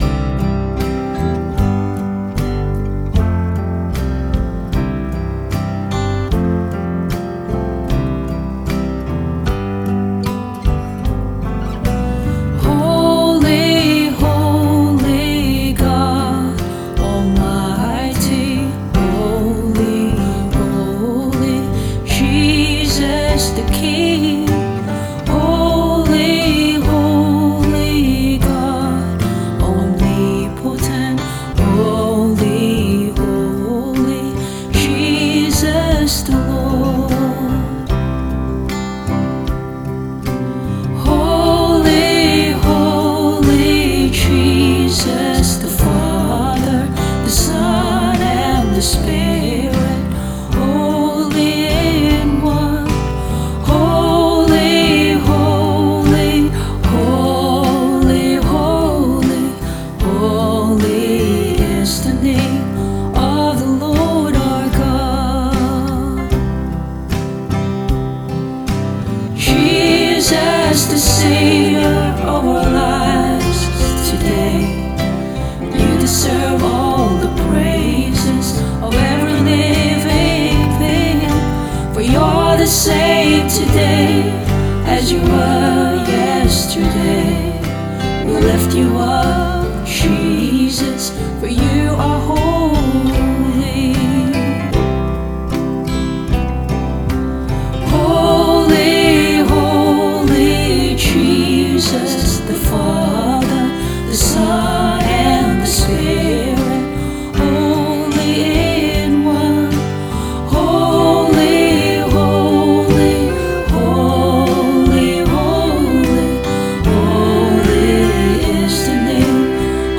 Vocals and Band-In-A-Box Arrangement